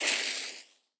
sfx_impact__fire_01.wav